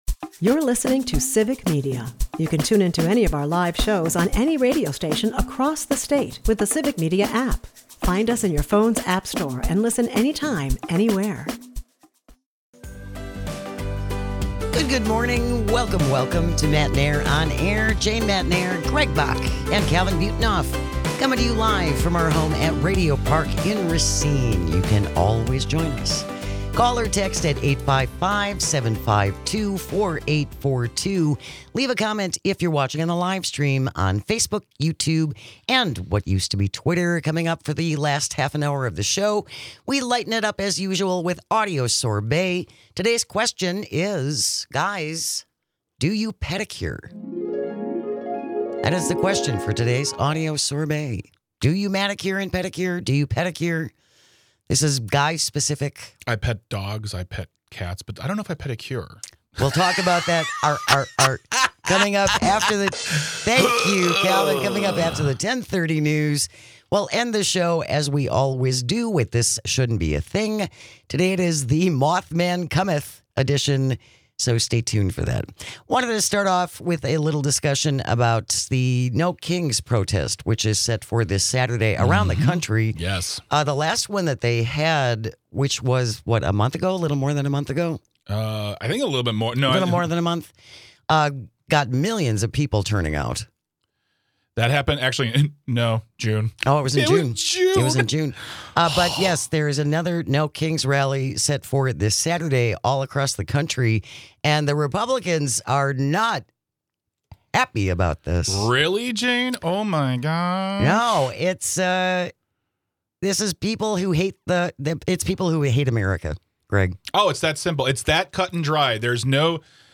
This Saturday there will be another No Kings Rally with events happening around the country and MAGA has opinions from the extreme lie to the extreme ridiculous. Also, Speaker Johnson thinks ICE is fine, but bikes in Portland is terrifying. We hear from you on what it means to love your country and why standing up to the powers that be is patriotic and necessary. For today's Audio Sorbet, we want to know: Do you pedicure?